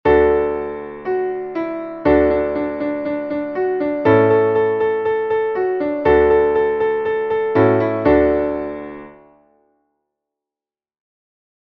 Traditionelles Tischlied